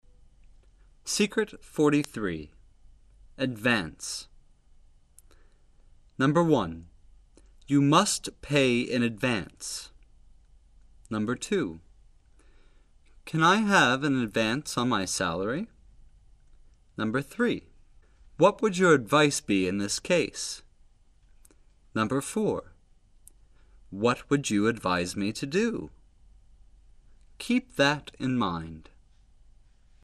爆破音和摩擦音相邻，第一个爆破音形成阻碍，发生不完全爆破，例如：